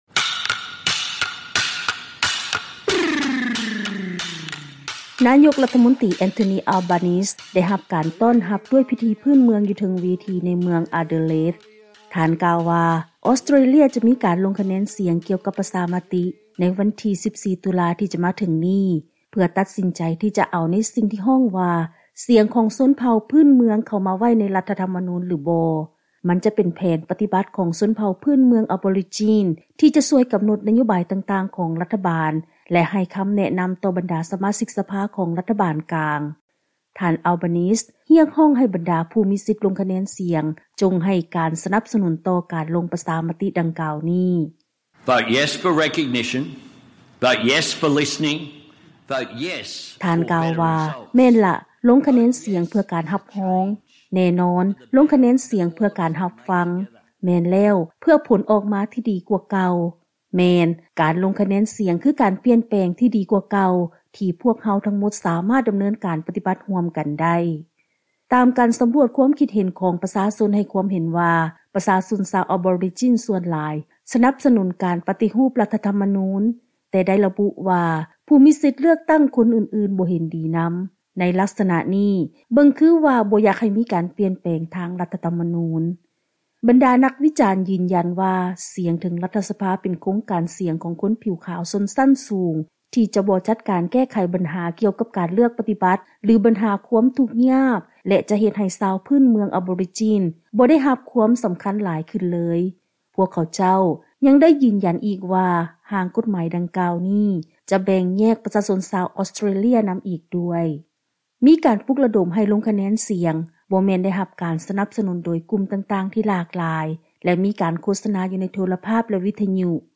ເຊີນຟັງລາຍງານ ການວາງແຜນລົງຄະແນນສຽງກ່ຽວກັບຮ່າງປະຊາມະຕິທີ່ສໍາຄັນ ສໍາລັບຊົນເຜົ່າພື້ນເມືອງ ຢູ່ໃນອອສເຕຣເລຍ